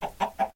latest / assets / minecraft / sounds / mob / chicken / say1.ogg